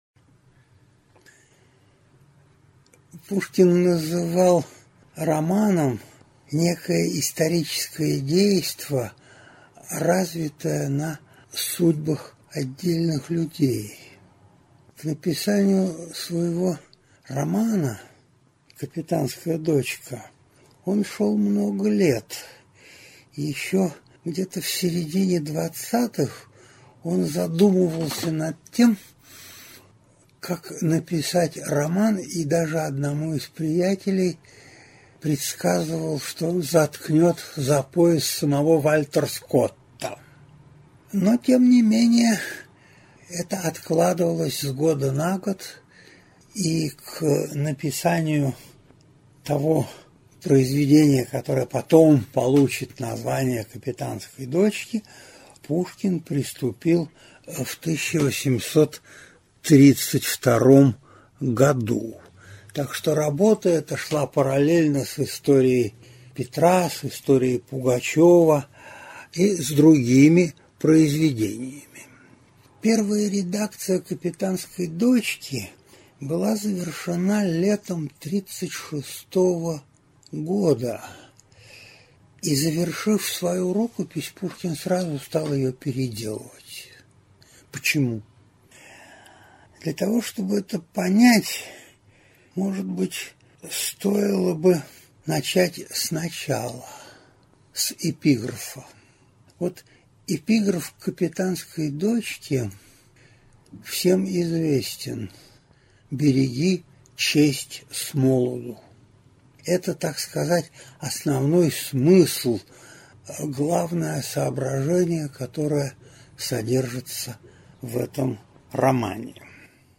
Аудиокнига Исторический роман «Капитанская дочка» | Библиотека аудиокниг